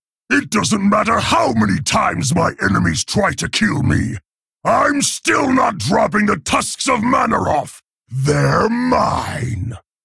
GarroshBase_Pissed06.ogg